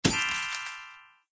SZ_DD_treasure.ogg